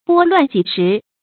拨乱济时 bō luàn jì shí
拨乱济时发音